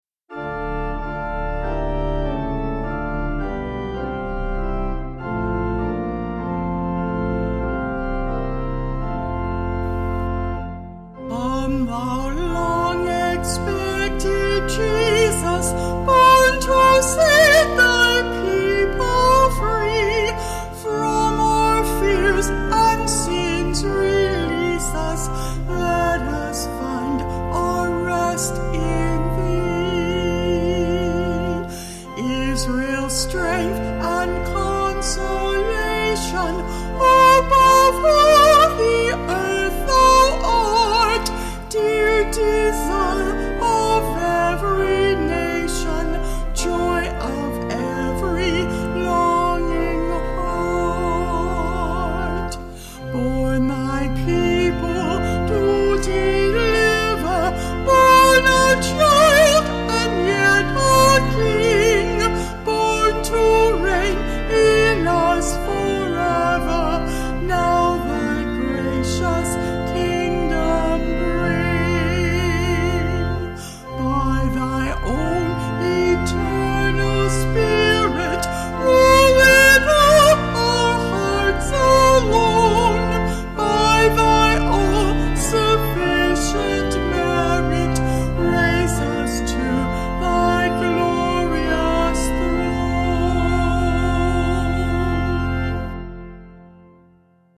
Vocals and Organ   195.6kb Sung Lyrics